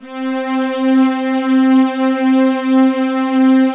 Strings (2).wav